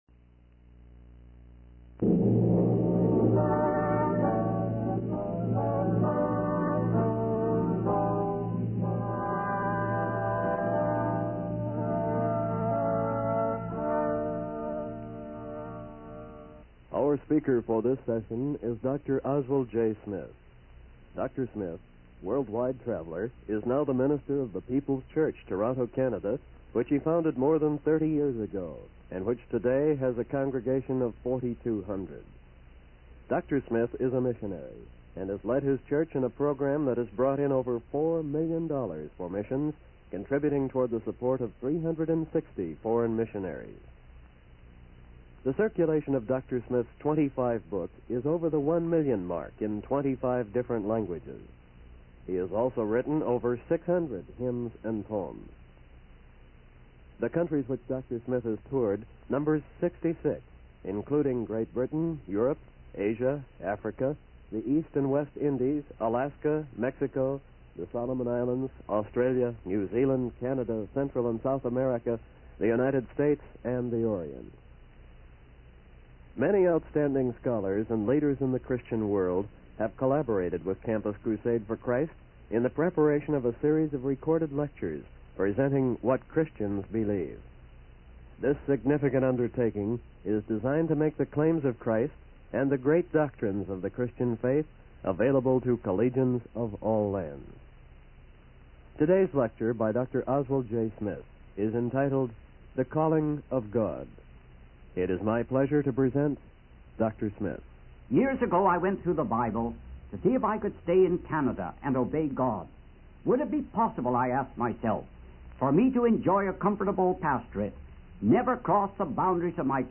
In this sermon, the speaker tells a parable about a master who returns to his estate and rewards his servants based on their faithfulness and diligence.